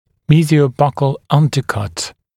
[ˌmiːzɪəu’bʌkl ‘ʌndəkʌt][ˌми:зиоу’бакл ‘андэкат]мезиально-щечное поднутрение